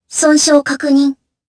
Kara-Vox_Damage_jp_02.wav